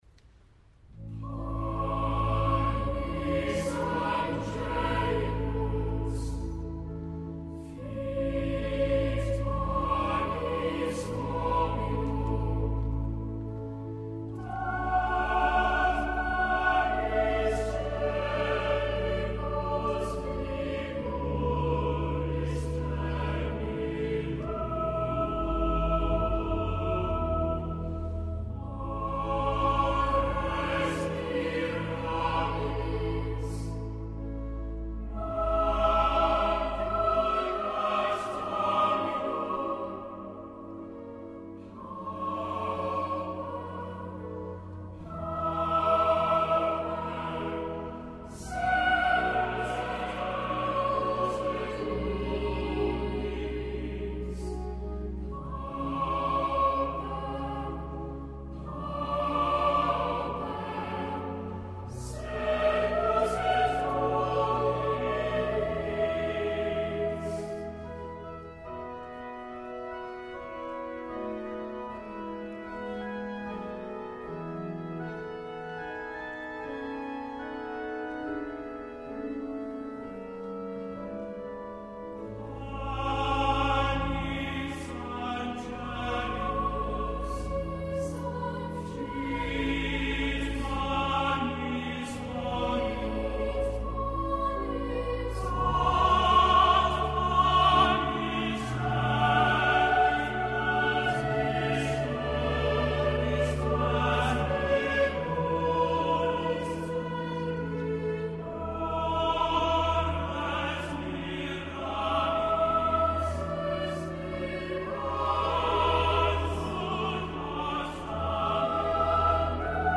Easter from King’s College, Cambridge
8          Choir: